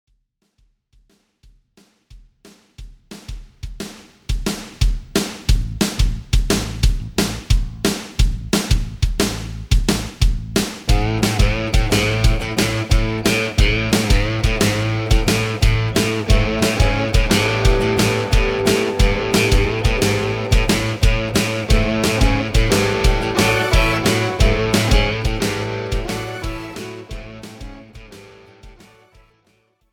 • Without Backing Vocals
• No Fade
Backing Track without Backing Vocals.